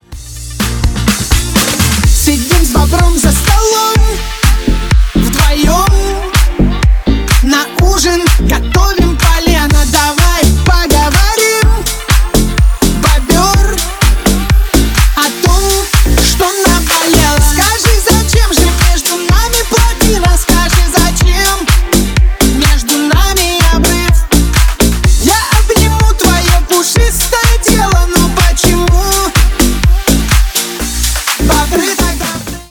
Громкая танцевальная песня доступна бесплатно.
Танцевальные рингтоны